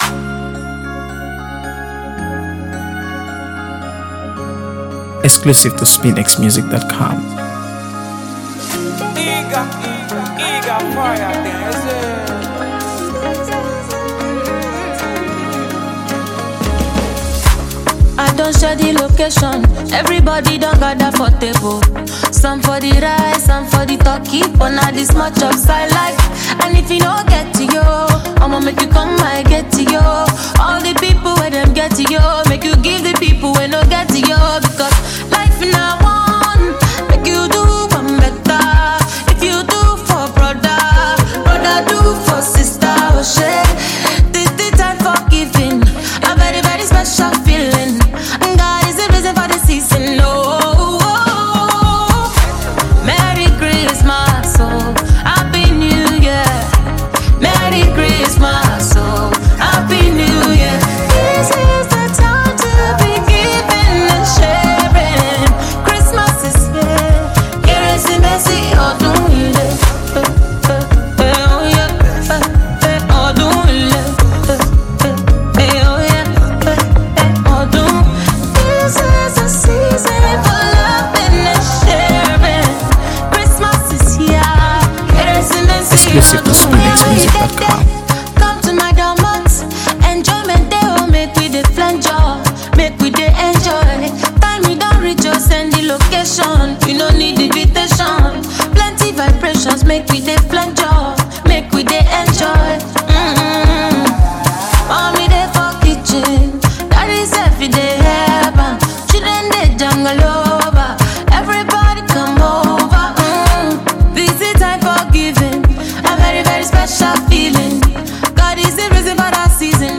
AfroBeats | AfroBeats songs
Exceptionally talented Nigerian singer and songwriter
infuses the song with warmth and joy